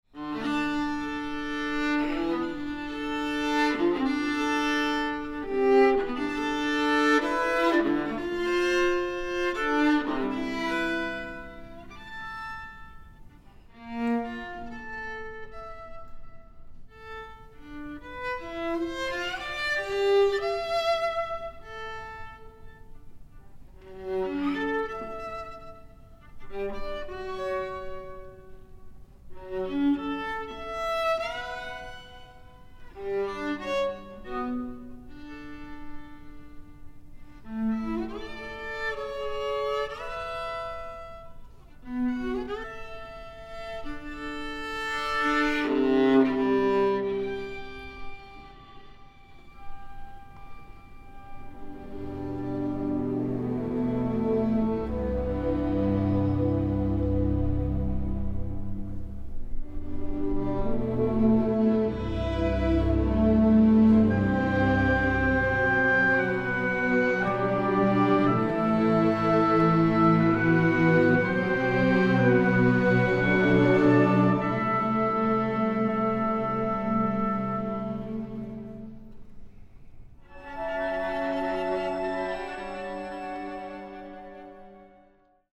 viola & chamber orchestra
(2222 2 hrn 2 tpt 2 prc strings)